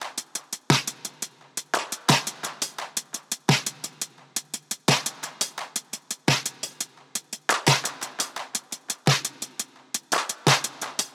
Index of /DESN275/loops/Loop Set - Futurism - Synthwave Loops
BinaryHeaven_86_TopDrums.wav